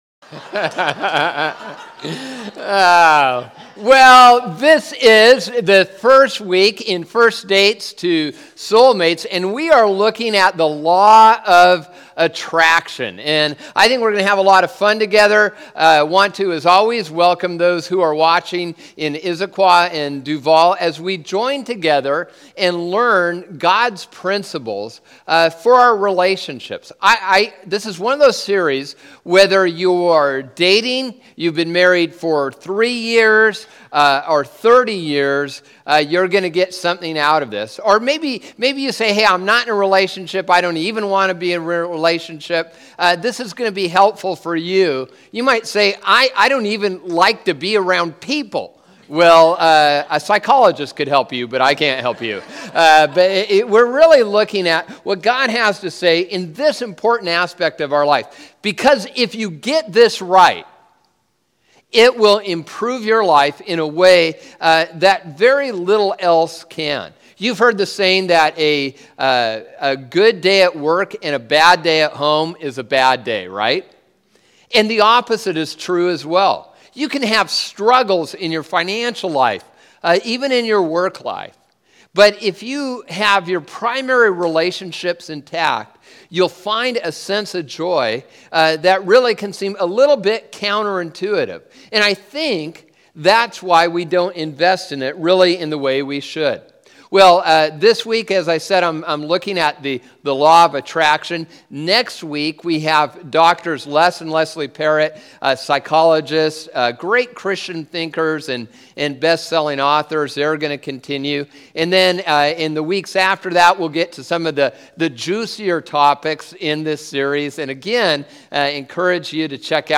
2-9-14_Sermon.mp3